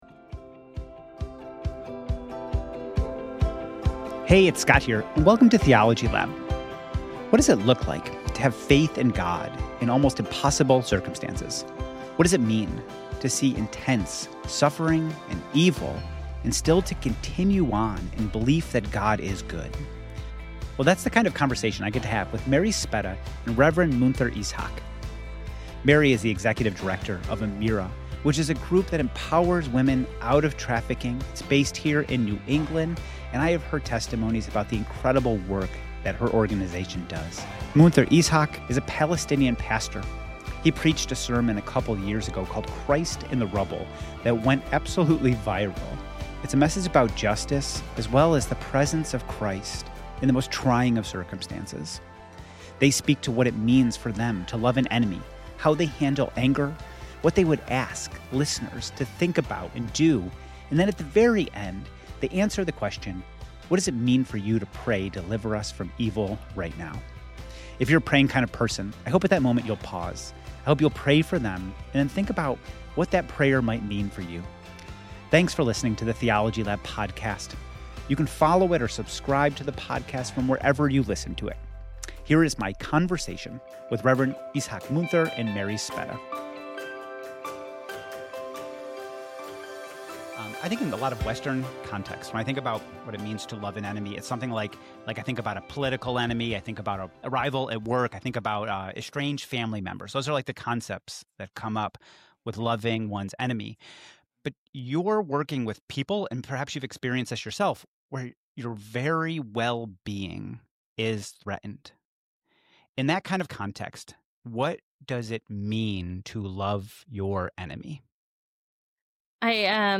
The conversation addresses theology and the message of Scripture.